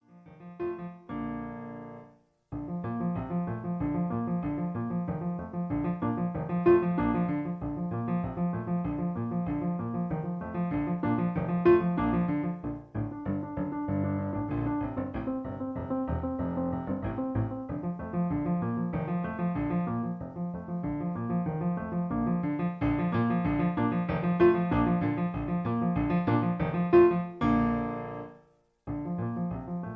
Original Key (B flat). Piano Accompaniment